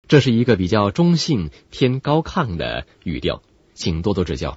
Professioneller chinesischer Sprecher für TV/Rundfunk/Industrie.
chinesischer Sprecher
Sprechprobe: Industrie (Muttersprache):